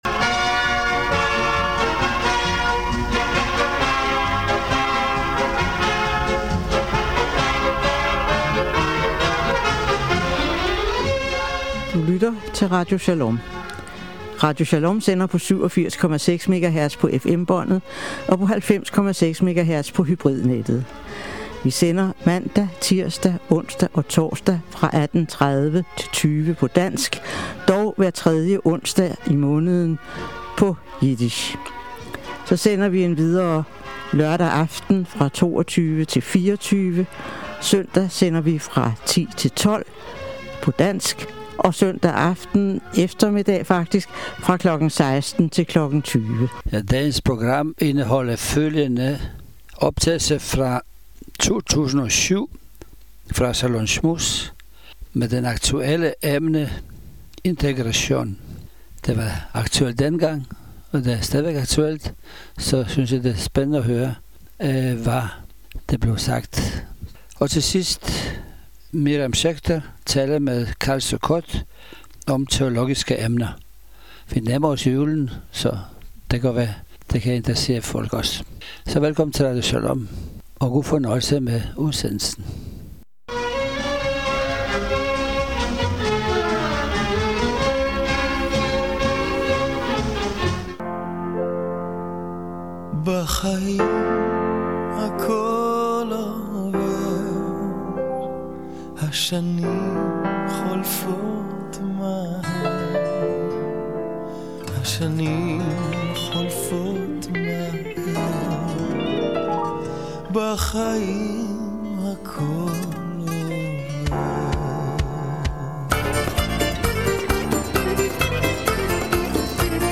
גם ידובר על ההפסקה בתנועה האוירית לישראל ומה חלקם של שרי החוץ של דנמרק ושל ישראל לגבי המצב בהווה ובעתיד. חיים טופול יספר על להקת הנח”ל במלאת 50 שנים לתקופתו בלהקה.